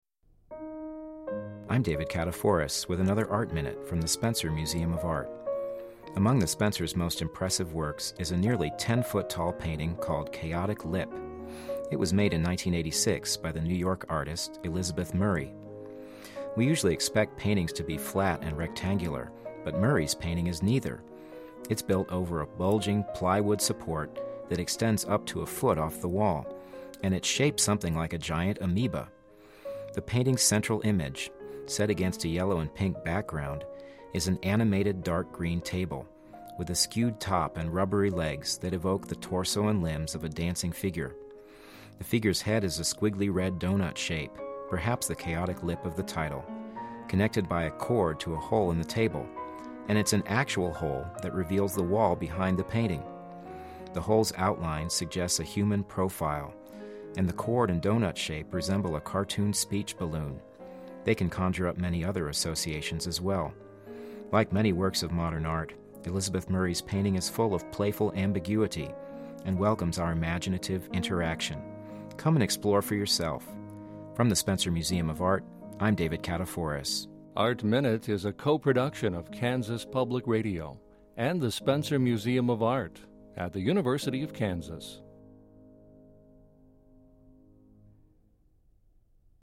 Didactic – Art Minute